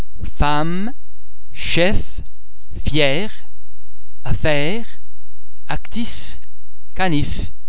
Please be mindful of the fact that all the French sounds are produced with greater facial, throat and other phonatory muscle tension than any English sound.
The French [f] and [ph] are normally pronounced [f] as in the English words flower, full, photo etc.
f_femme.mp3